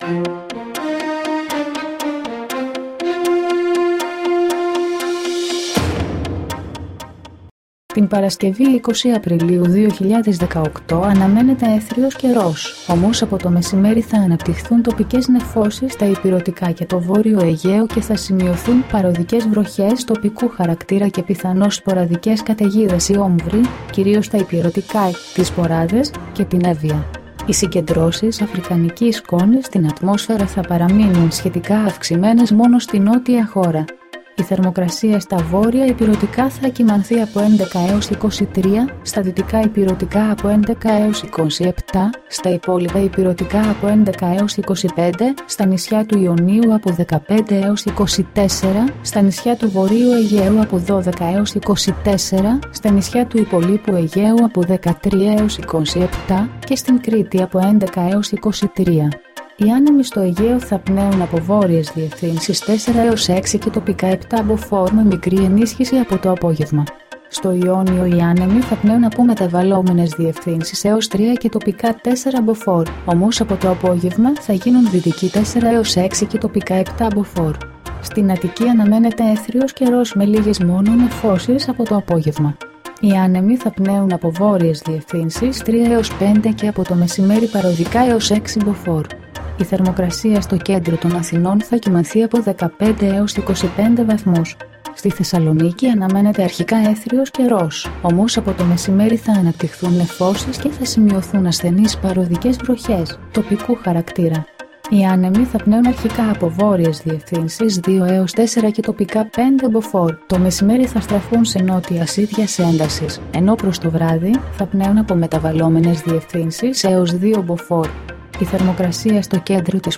dailyforecastllll.mp3